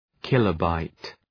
Προφορά
{‘kılə,baıt}